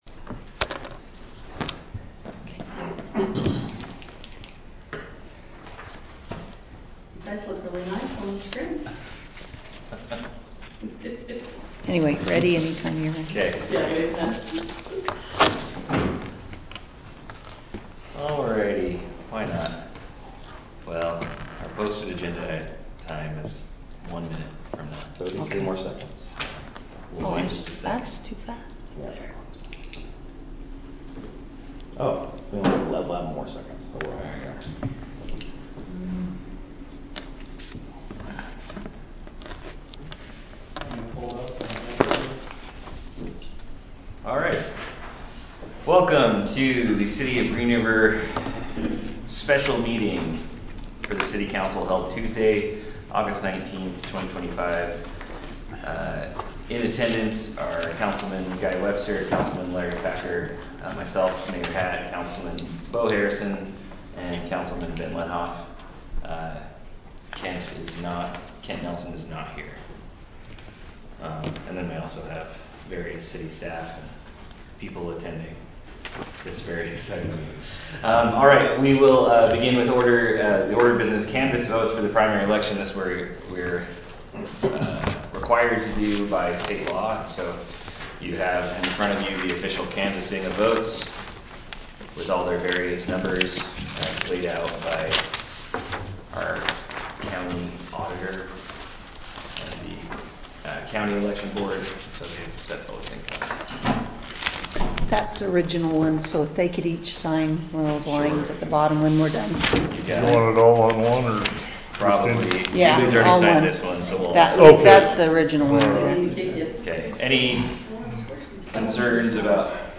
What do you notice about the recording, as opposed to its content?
Green River City Council City Council Special Meeting